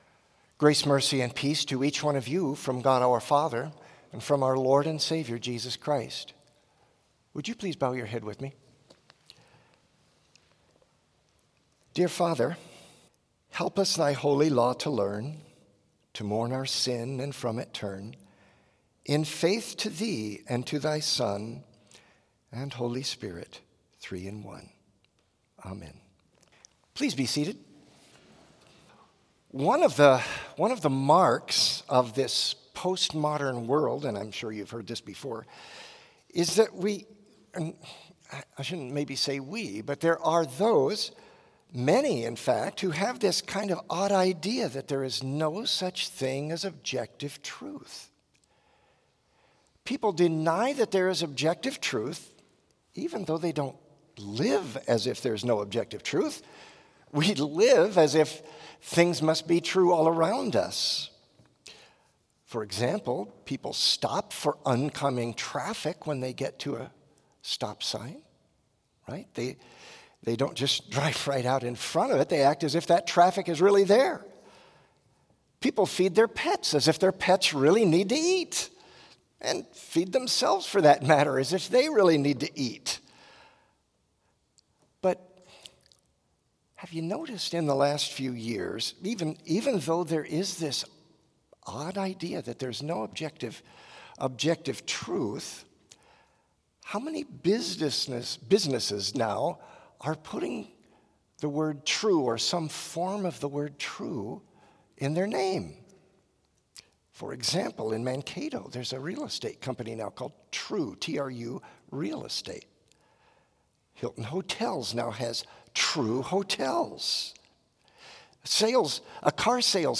Complete service audio for Chapel - Tuesday, October 31, 2023
Hymn 551 - Lord, Help Us Ever To Retain
Devotion Prayer Hymn 250 - A Mighty Fortress is Our God View vv. 3 & 4 Blessing Postlude